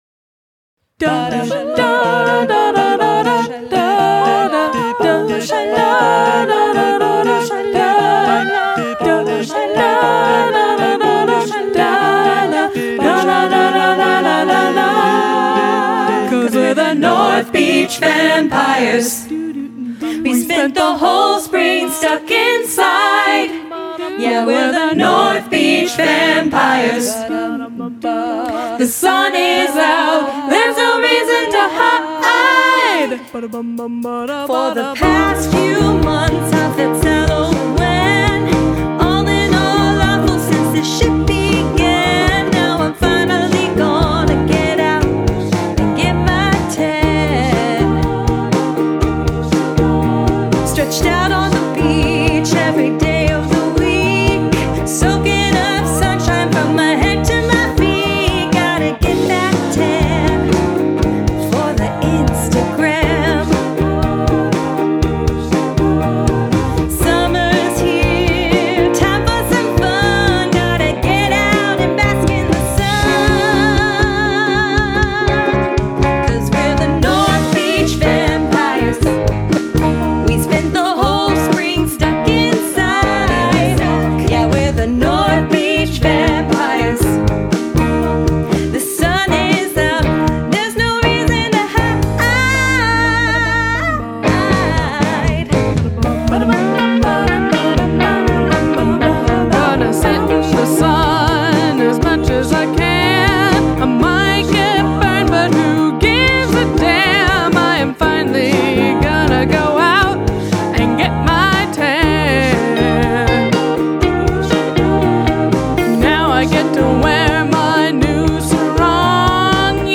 Include an a cappella section
I like that old timey rock ‘n’ roll guitar, sounds sweet.